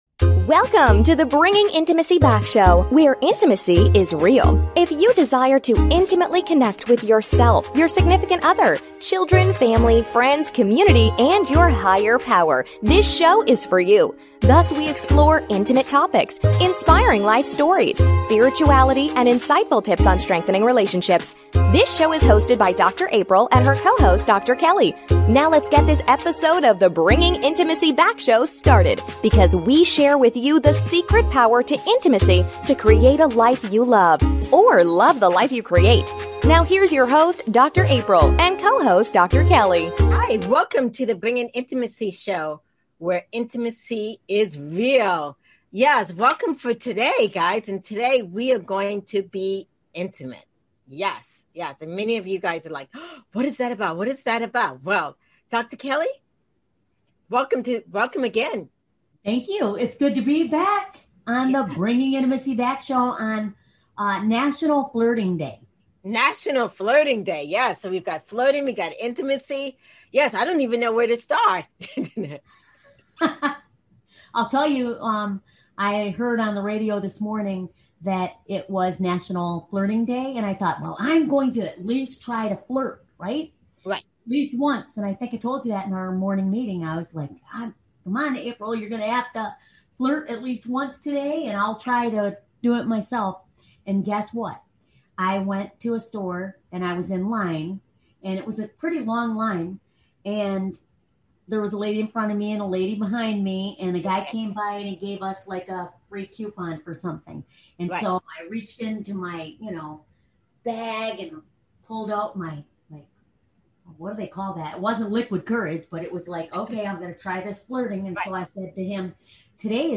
Talk Show Episode, Audio Podcast, Bringing Intimacy Back